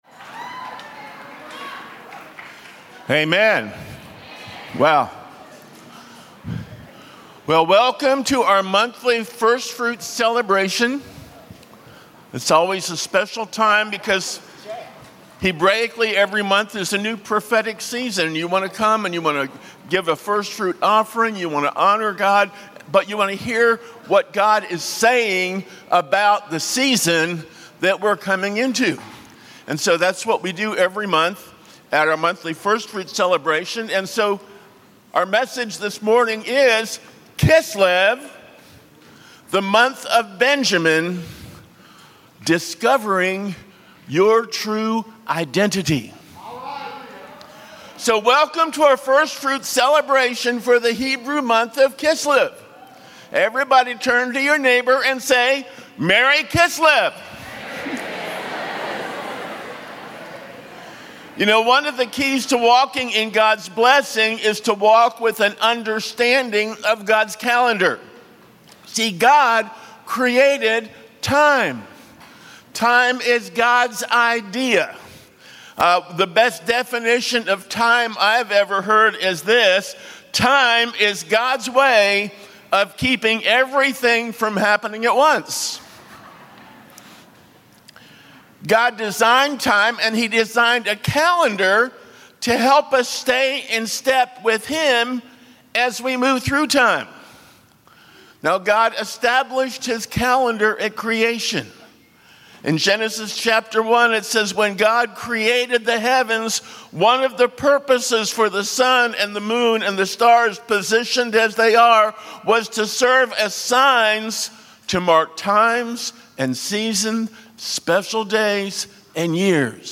Sunday Celebration Service